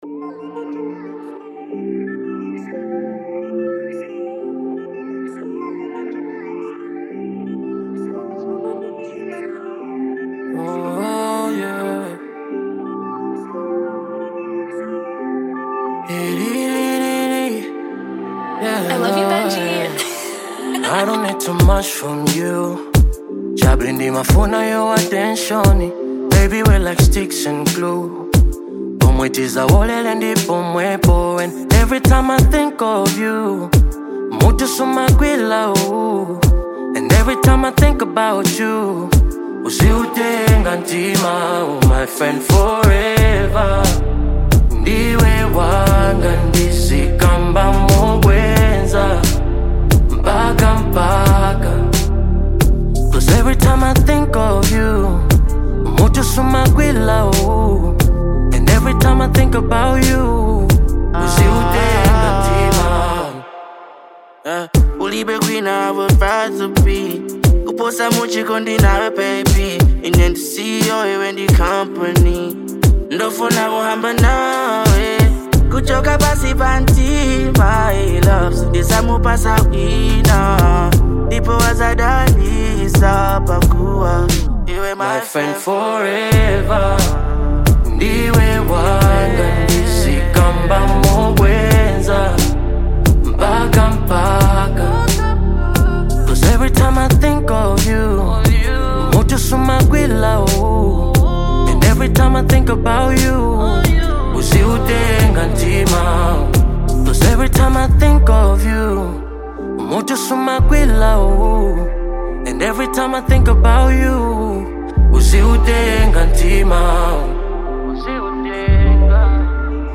Genre : RnB
With soft Afro-soul instrumentation and gentle rhythms
creates a calming yet thought-provoking atmosphere